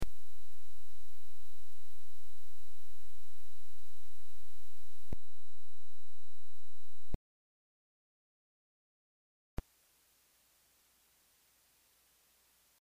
I am using AIC3204 and meet ADC mute pop sound.
The attachment MP3 is what I did about the issue, you can find L channel is DC free, but pop sound happen while mute (5s), and unmute(9.5s); R channel is DC biased, pop sound occurs while mute (7.5s)...